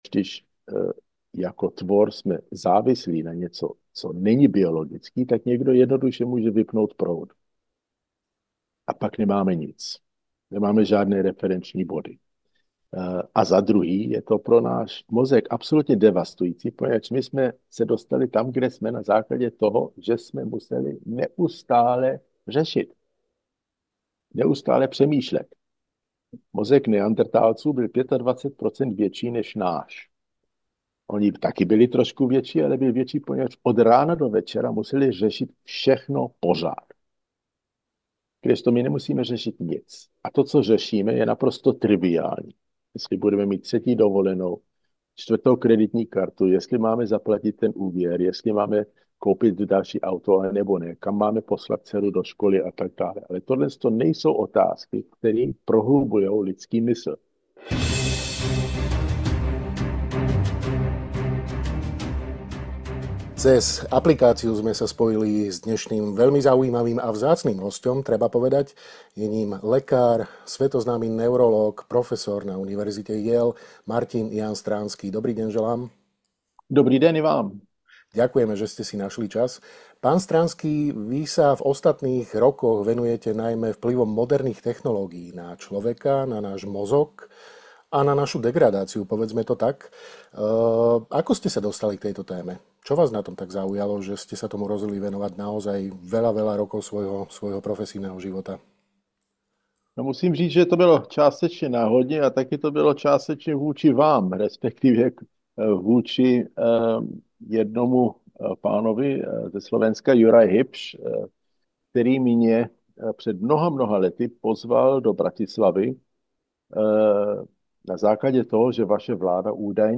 Viac vo videorozhovore.